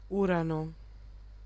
Ääntäminen
US : IPA : [ju.ˈɹeɪ.nəs] RP : IPA : /ˈjʊɹ.ə.nəs/